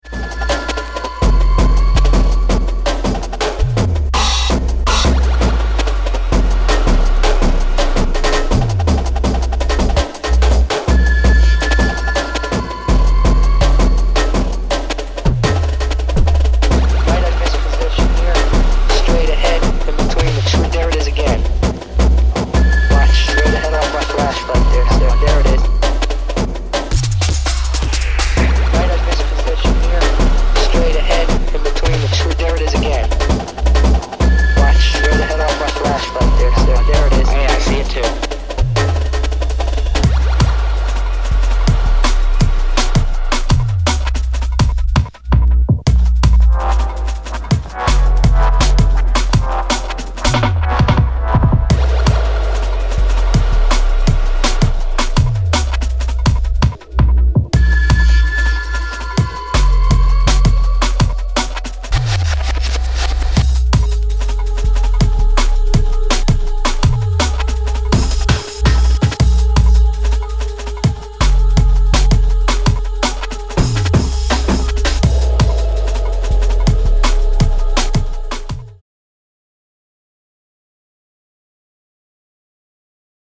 Jungle/Drum n Bass